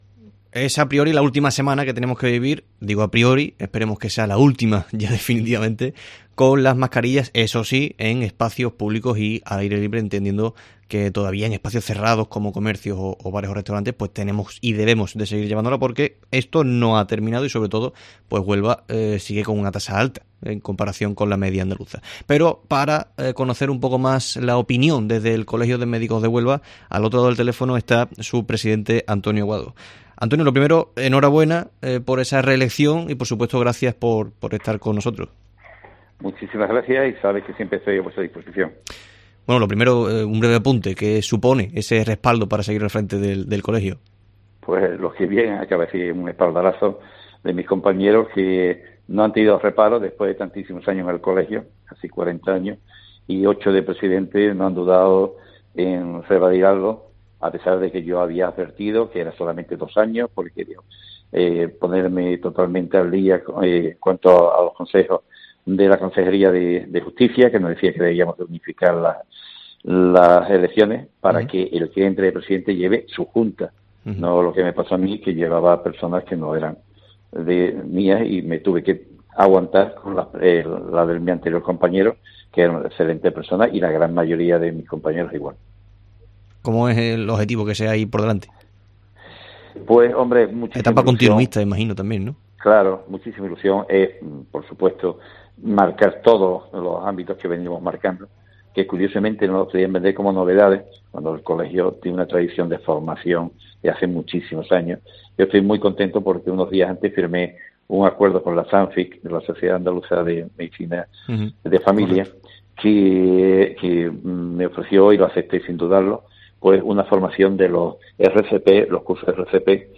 ha atendido la llamada de COPE Huelva